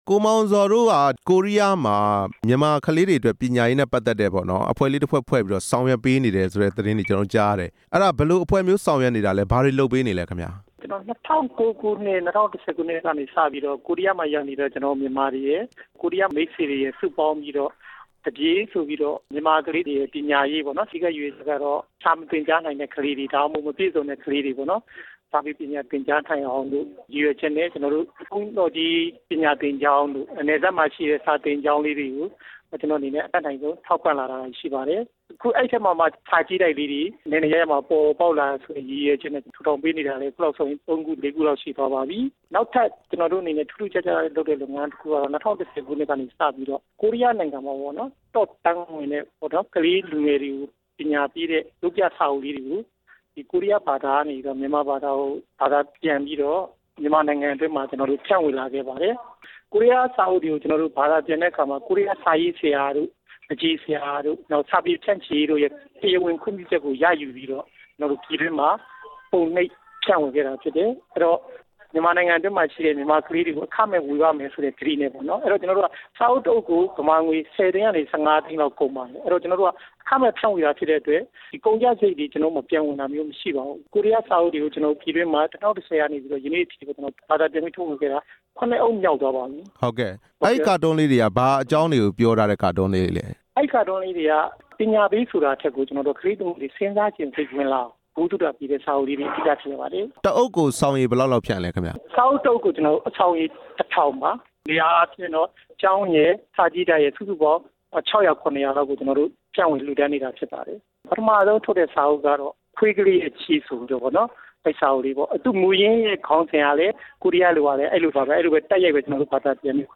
သပြေဖောင်ဒေးရှင်းနဲ့ ဆက်သွယ်မေးမြန်းချက်